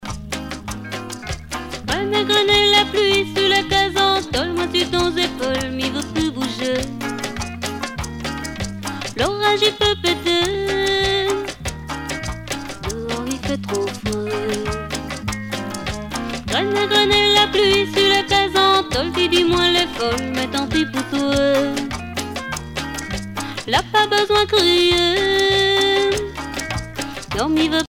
danse : séga
Pièce musicale éditée